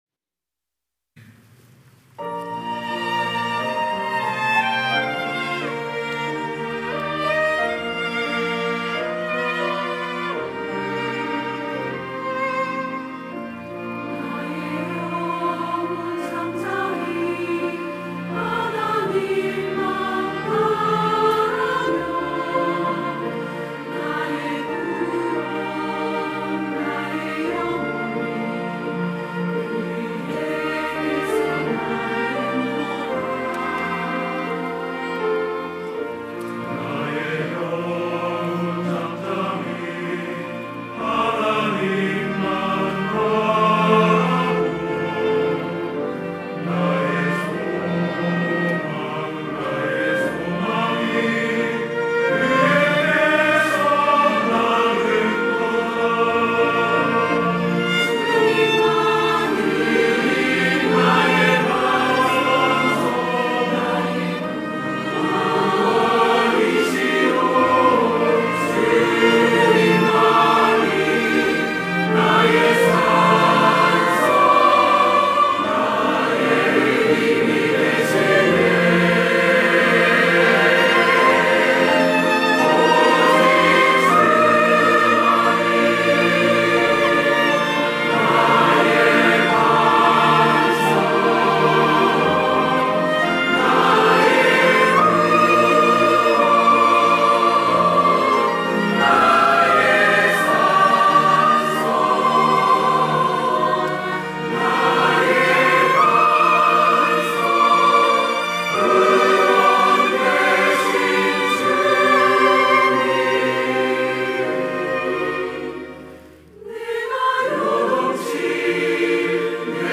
호산나(주일3부) - 시편 62편
찬양대 호산나